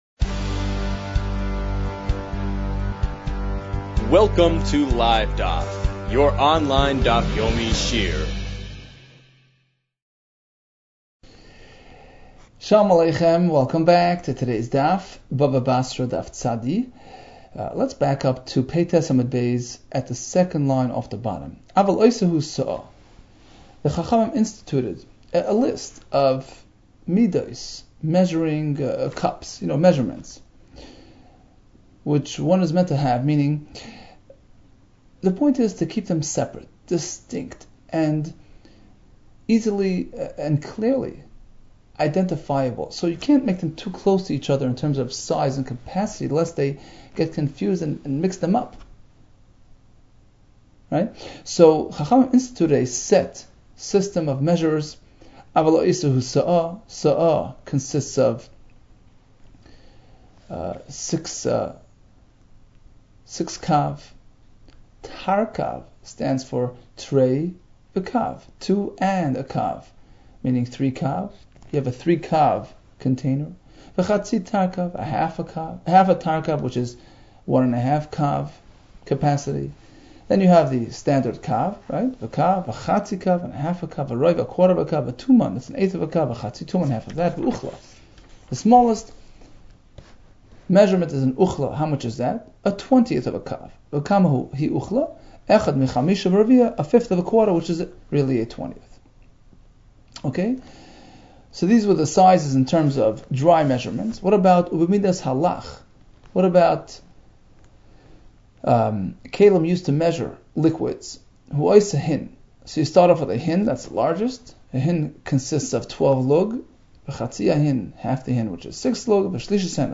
Bava Basra 90 - בבא בתרא צ | Daf Yomi Online Shiur | Livedaf